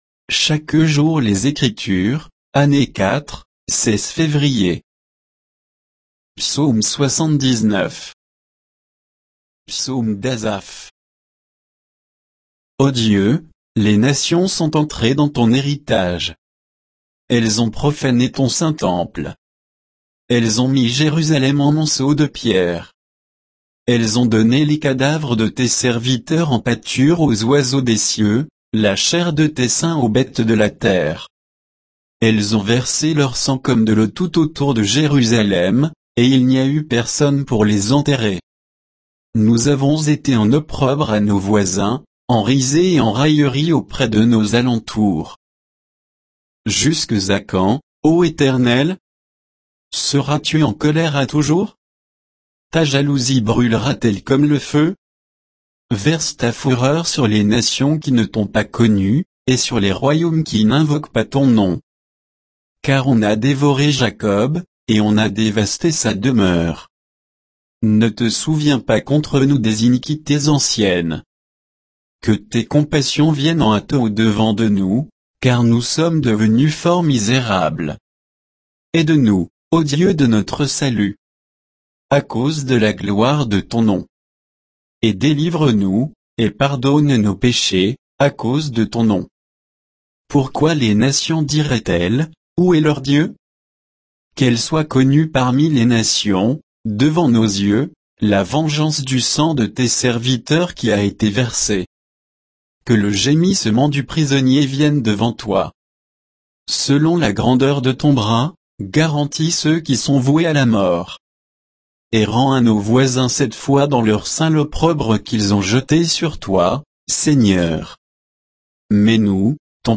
Méditation quoditienne de Chaque jour les Écritures sur Psaume 79